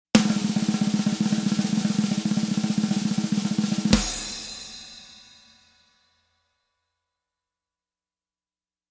DRUMROLL
Tags: party sounds